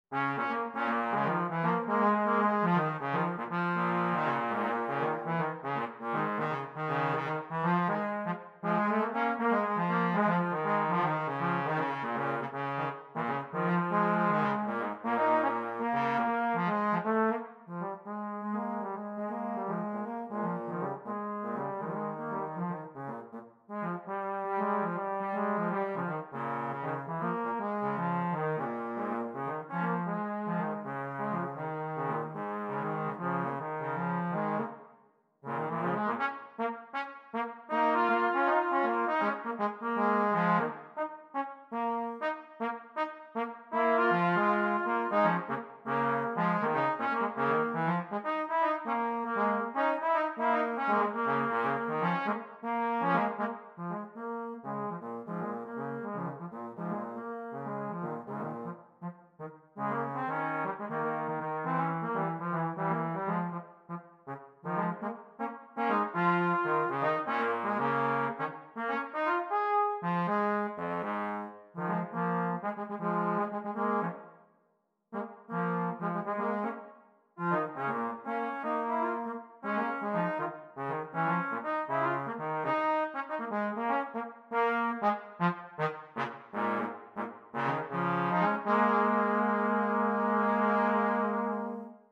Gattung: Für 2 Posaunen
Besetzung: Instrumentalnoten für Posaune